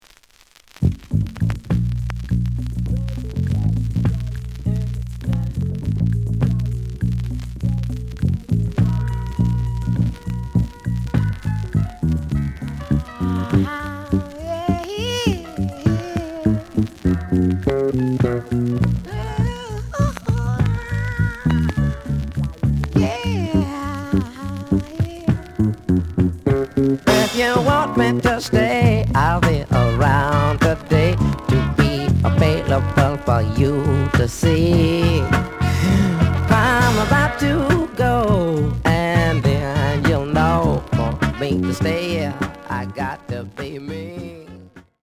The audio sample is recorded from the actual item.
●Genre: Soul, 70's Soul
Some noise on A side.)